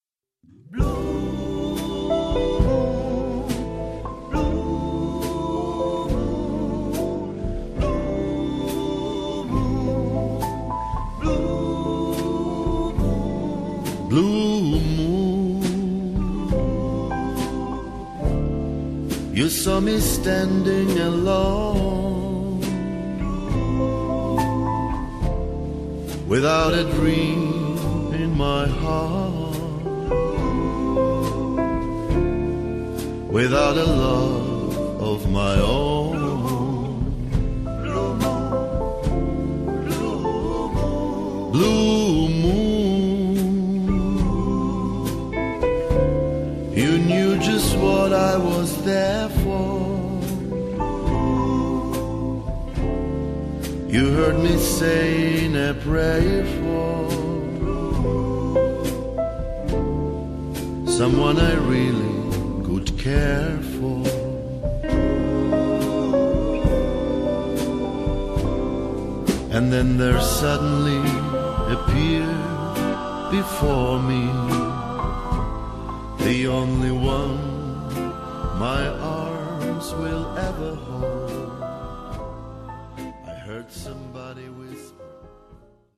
• Pianist / Orgelspieler